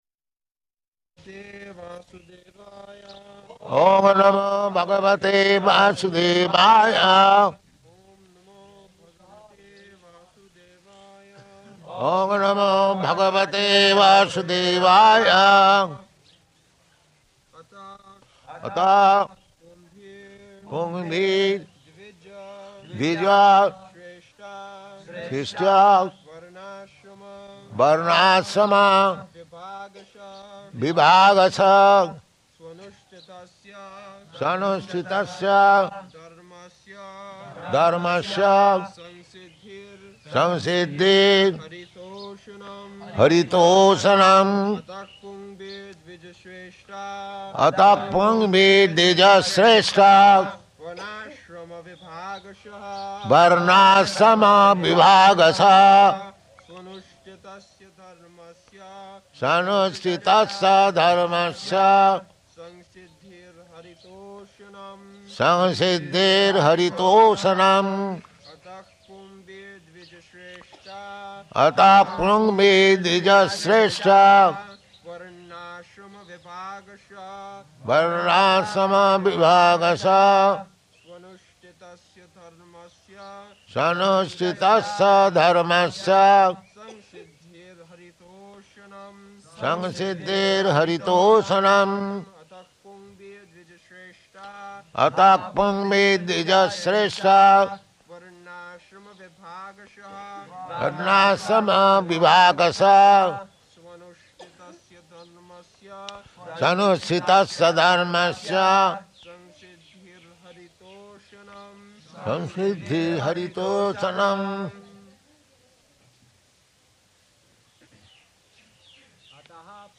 October 24th 1972 Location: Vṛndāvana Audio file
[leads chanting of verse] [Prabhupāda and devotees repeat]